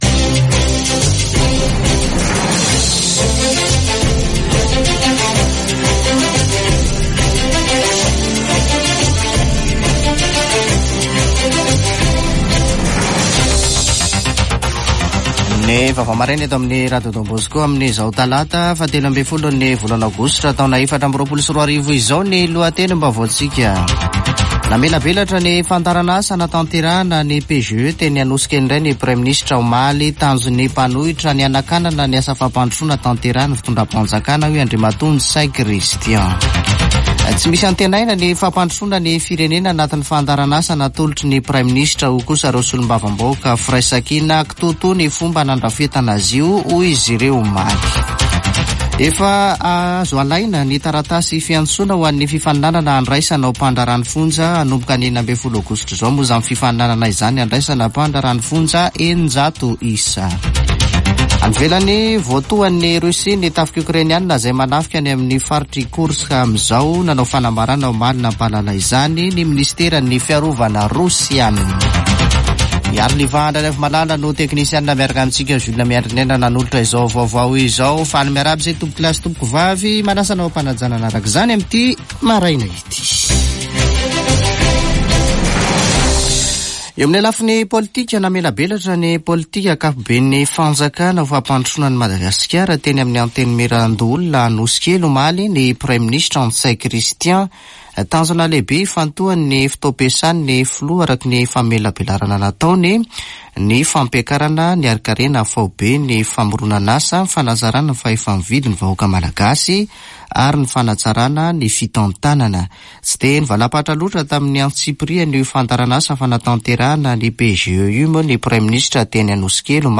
[Vaovao maraina] Talata 13 aogositra 2024